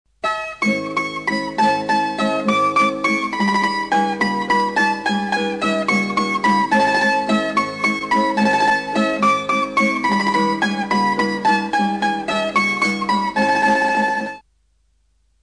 bandurria.mp3